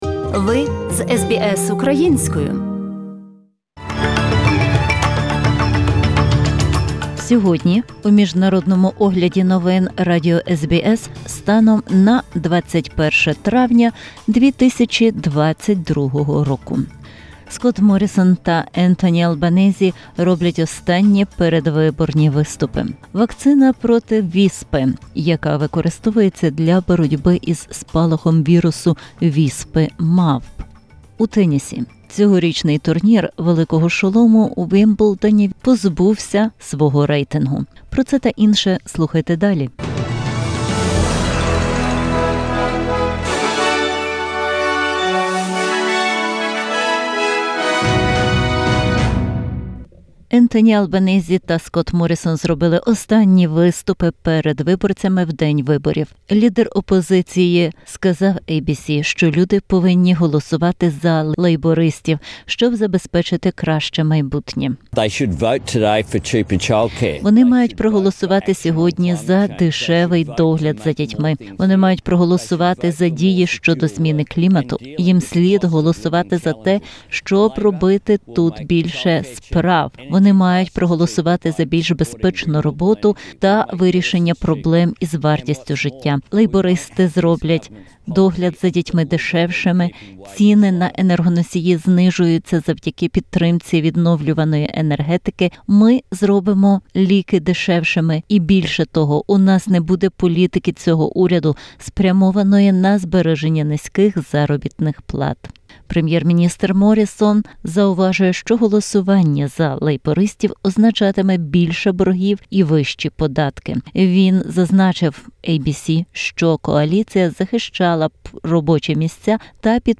In this bulletin: 1. Polling booths open across Australia for the federal election. 2. The smallpox vaccine being used to control the outbreak of the monkeypox virus and in tennis, this year's Wimbledon grand slam stripped of its rankings.